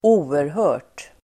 Uttal: [²'o:erhö:r_t]